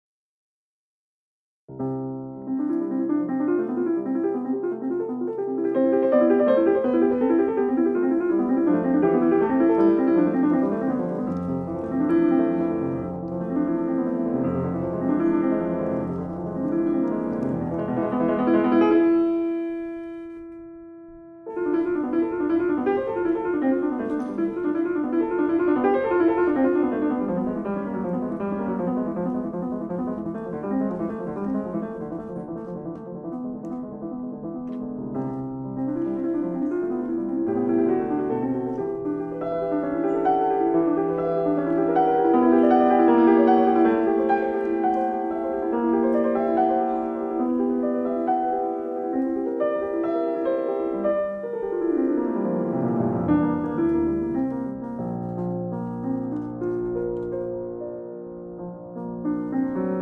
Suite for Piano
Modérément animé   2:12